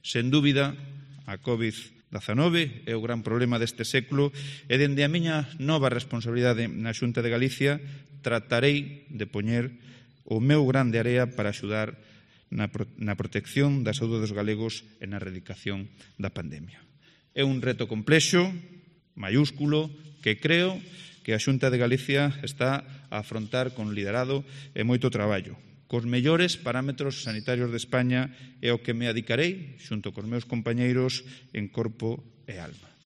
Primeras declaraciones de Luis López como nuevo delegado de la Xunta en Pontevedra